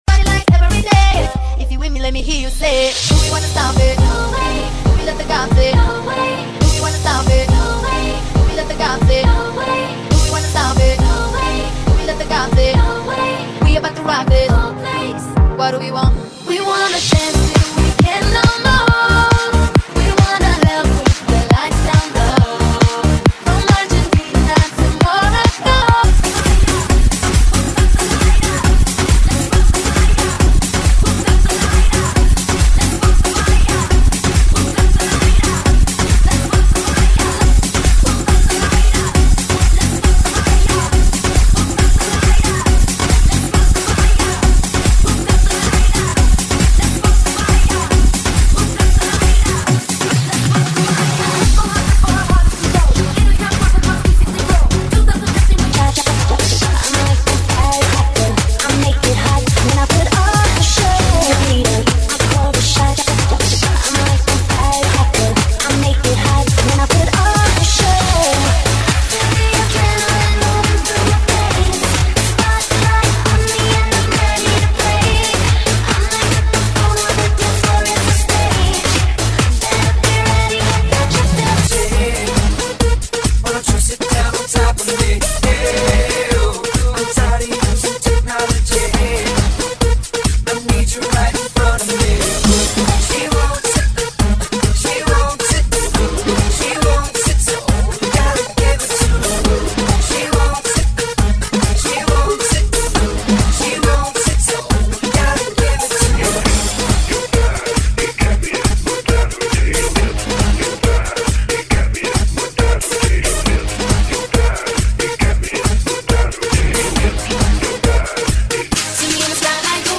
GENERO: POR MUSIC, ELECTRO, REMIX, VARIOS ARTISTAS, INGLES
Velocidad BPM